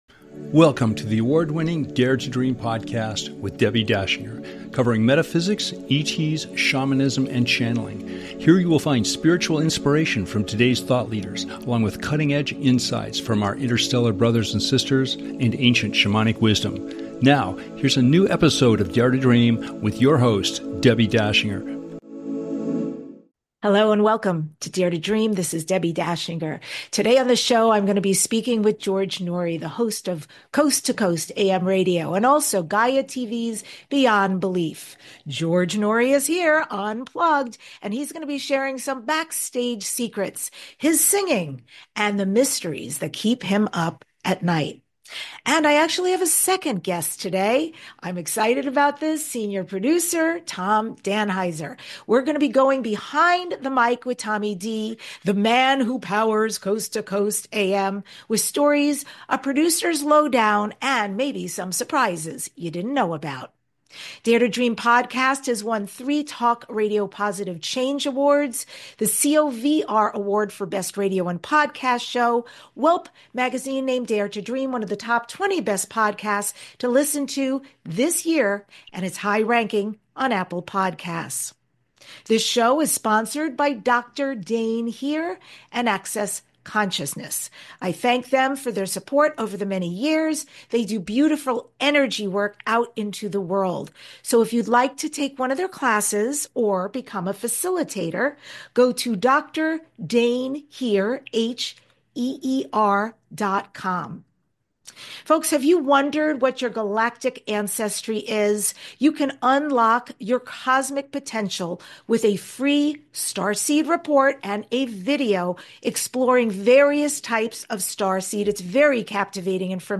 In this exclusive interview, we uncover: